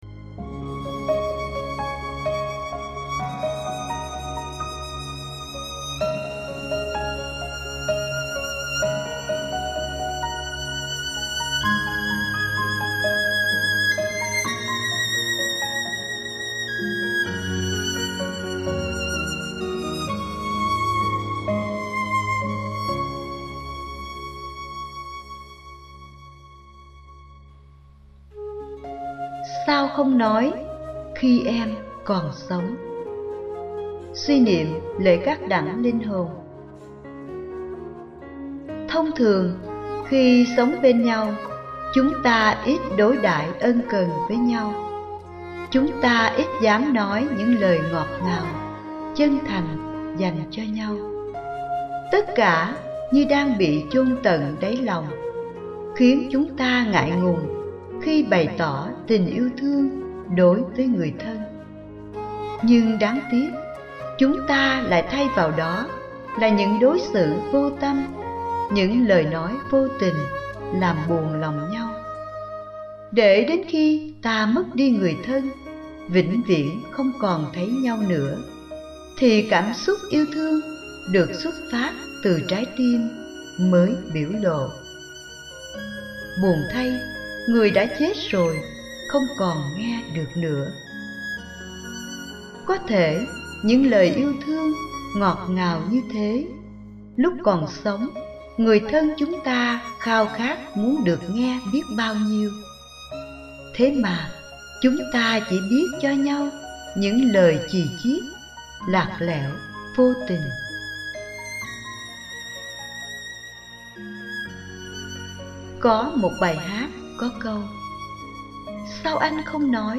Sao không nói khi em còn sống? (suy niệm lễ các Đẳng Linh Hồn)